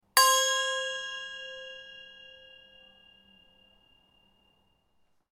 Clean-and-sharp-metal-ding-sound-effect.mp3